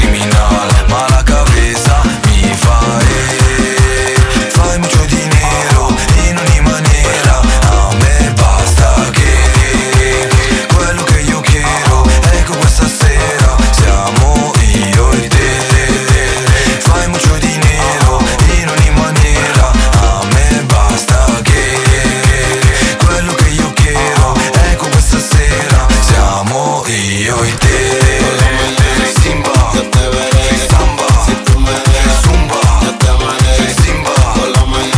Urbano latino
Жанр: Латино